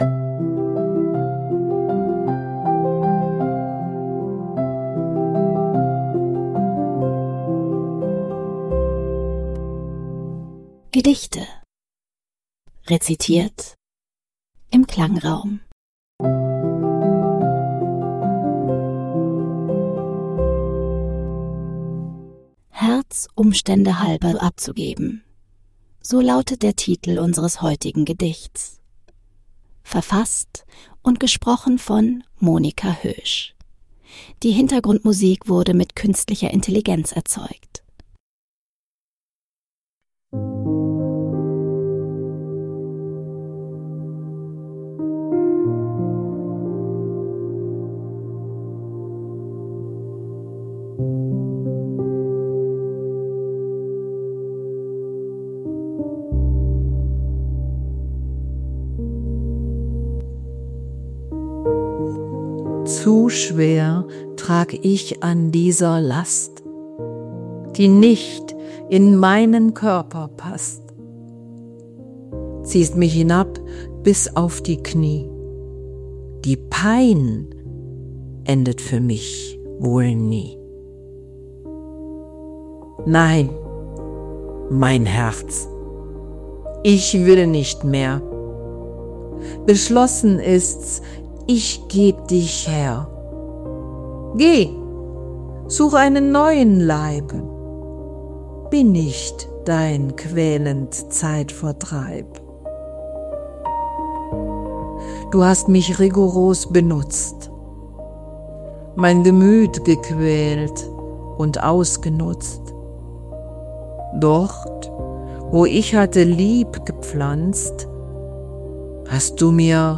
Die Hintergrundmusik wurden mit KI